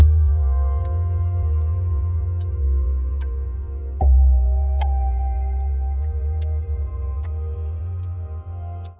Next-GPT Output – I guess you may want some musics that sound soft.